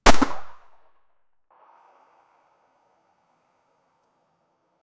556-single-gun-shot-with-qsvlxsg4.wav